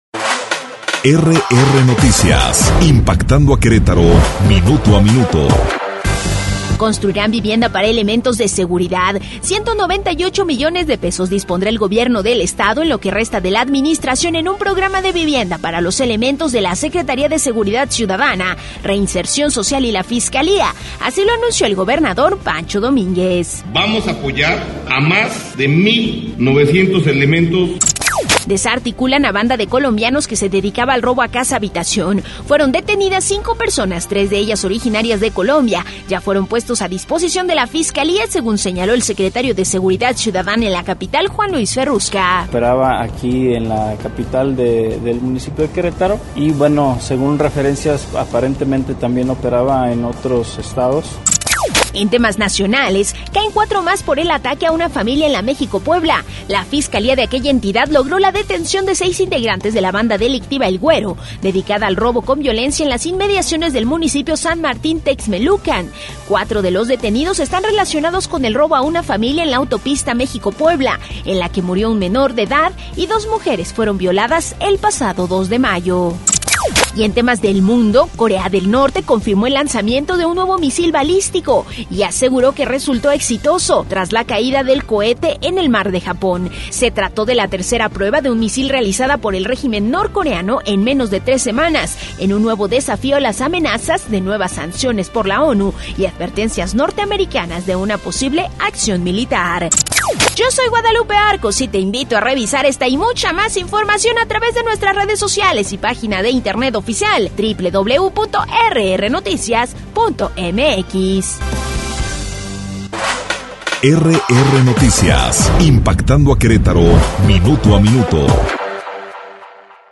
Resumen Informativo 30 de mayo - RR Noticias